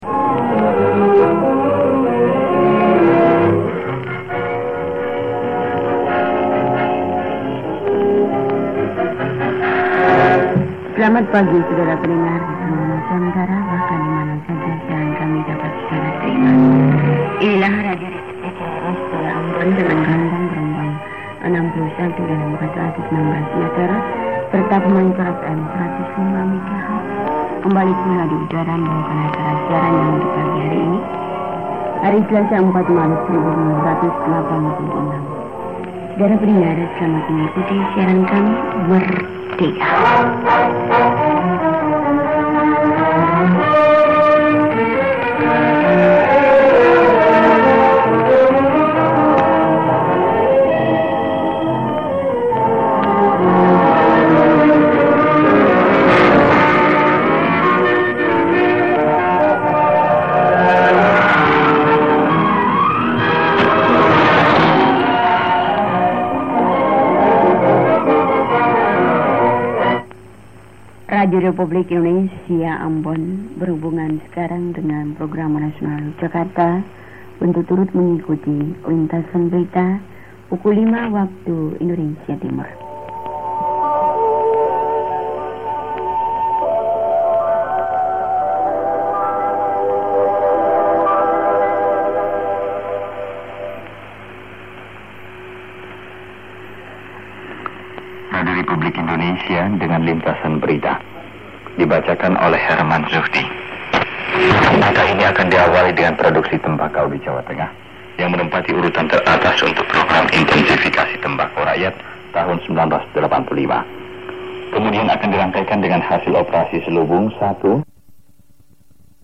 短波放送の録音をMP3でアップしました。
開始音楽もイイ。